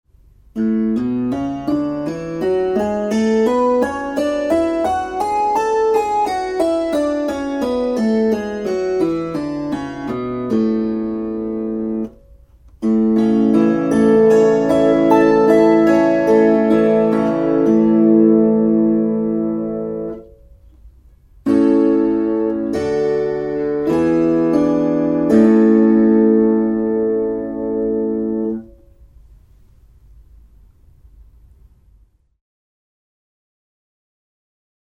Kuuntele A-duuri. gis fis cis Opettele duurit C G D E F B Es As mollit a e h fis cis d g c f Tästä pääset harjoittelun etusivulle.